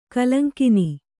♪ kalaŋkini